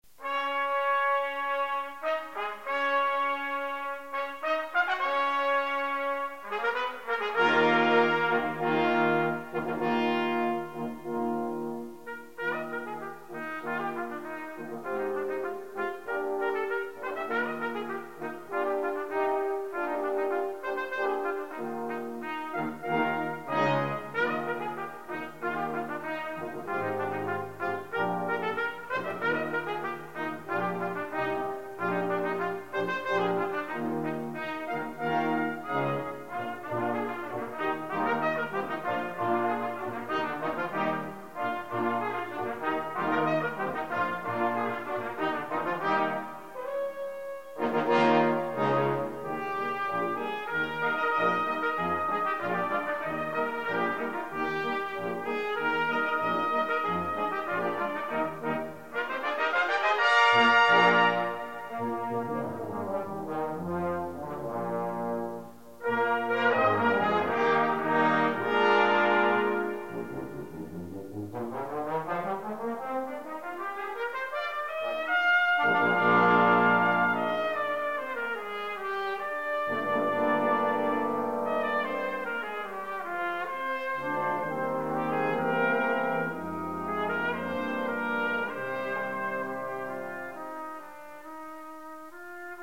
10 parts. 4 Trp, Horn, 4 Trb, Tuba.